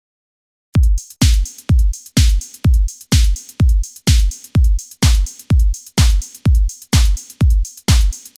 Alle Soundbeispiele aus diesem Elektrobeat Tutorial stammen aus dem Korg Kronos 2.
Die Hits kommen hier auf die 2 und auf die 4: Kick – Snare – Kick – Snare.
4. Clap
Hier hörst Du zuerst den Beat ohne Clap und dann mit:
05__clap__beats_bauen_synthesizer.mp3